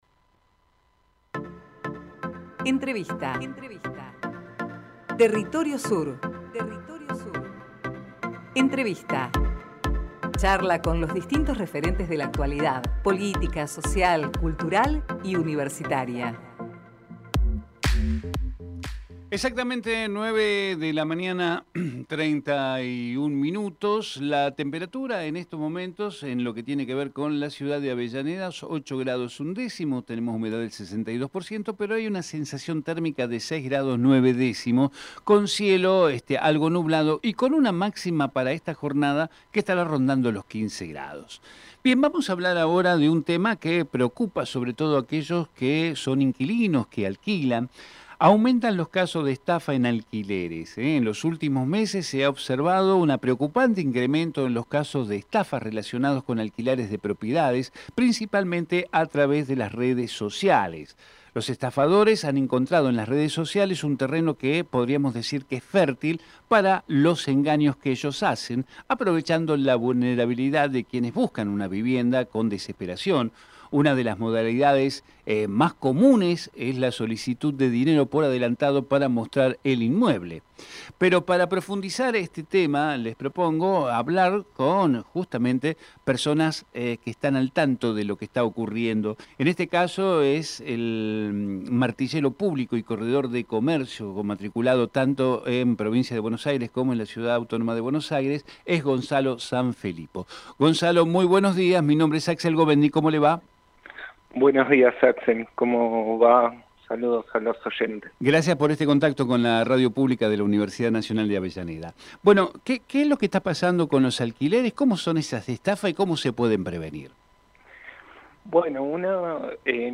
Compartimos entrevista